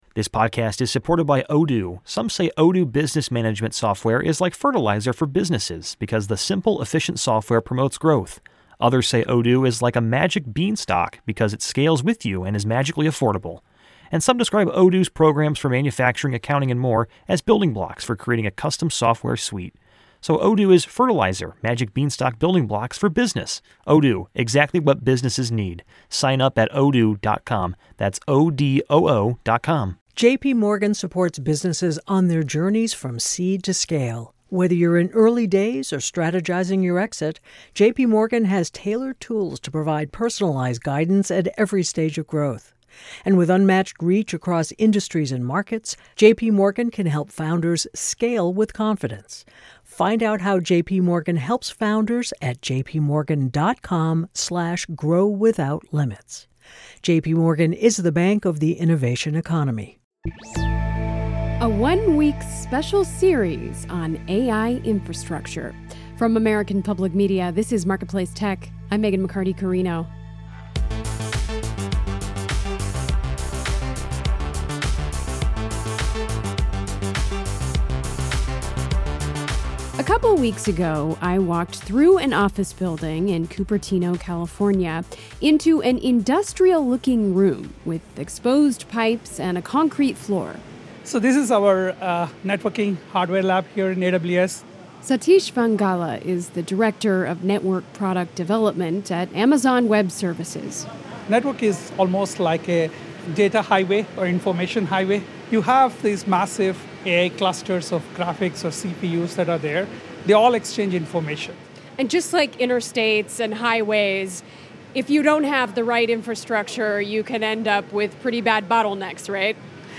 Is Amazon Web Services developing its own, presumably proprietary, high-density fiber optic cable packages? I have my doubts, but that’s what is said in this Marketplace report.